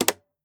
switch_on.wav